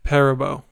Piper Lisa Perabo[1] (/ˈpɛrəb/
En-us-Perabo.ogg.mp3